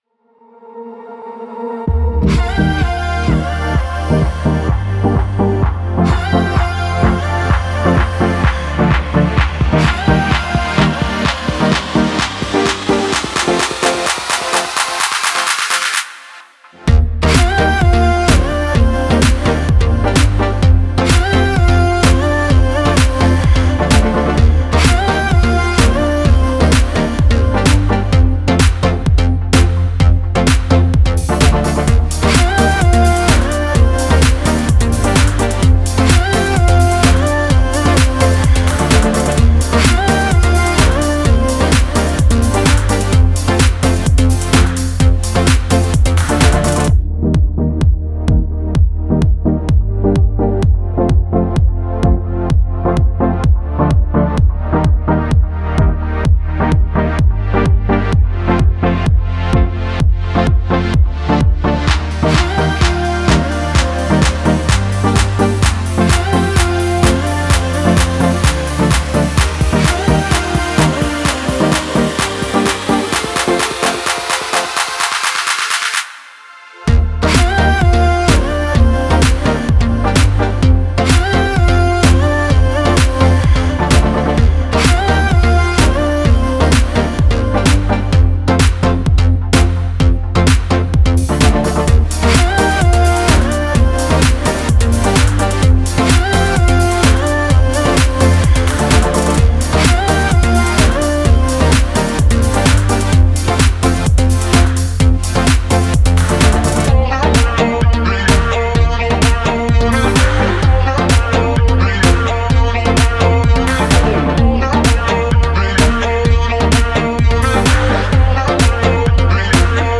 SUMMER EDM
Positive / Party / Pop / Banger